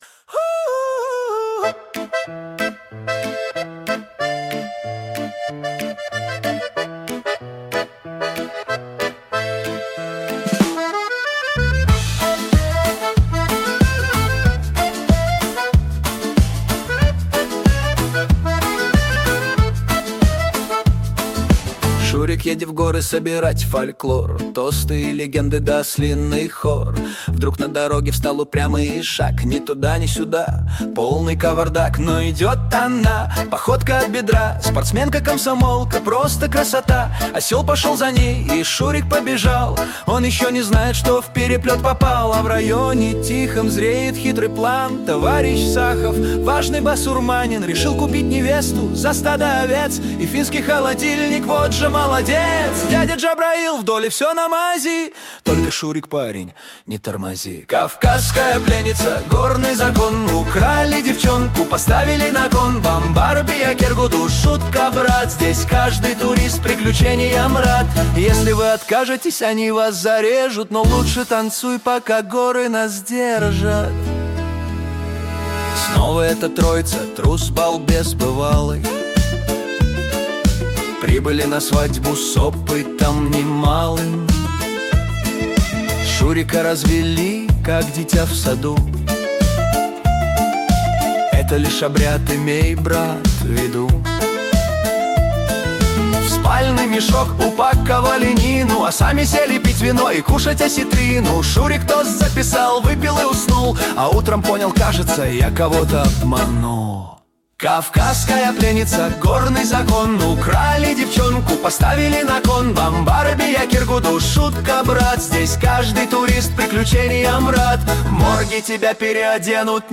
звучат тосты, дудук, барабаны, серф-рок и твист 60-х,